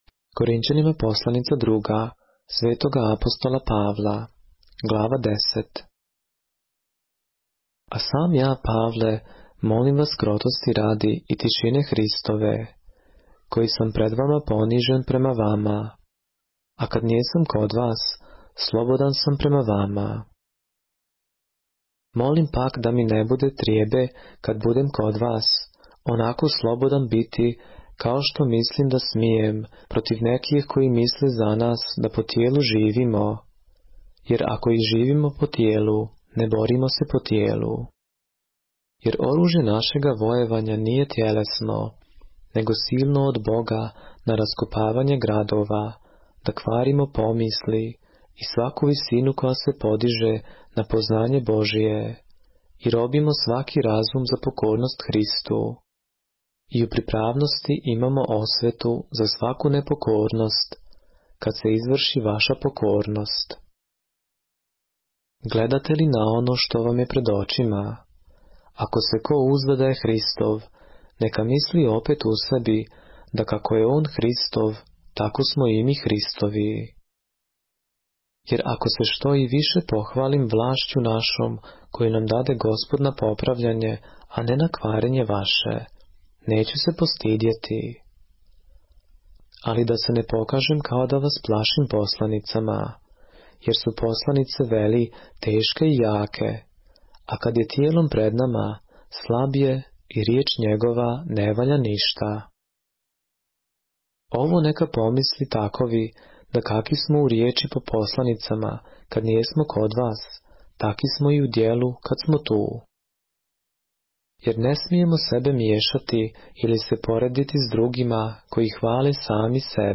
поглавље српске Библије - са аудио нарације - 2 Corinthians, chapter 10 of the Holy Bible in the Serbian language